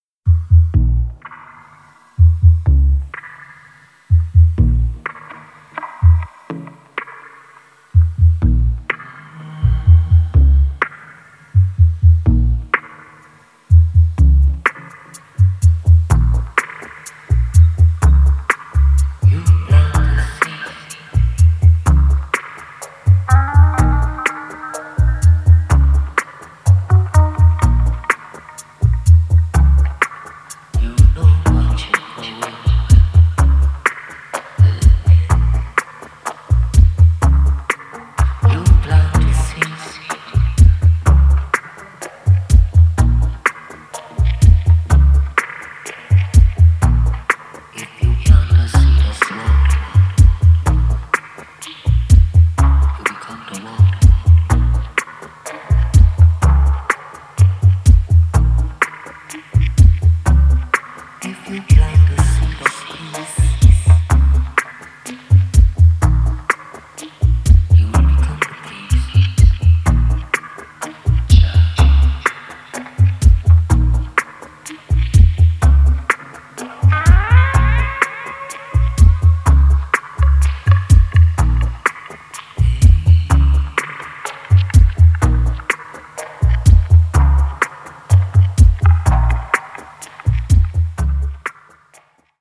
無駄無く削ぎ落とされたモダンでミニマルな音響から浮かび上がるアーシーな郷愁。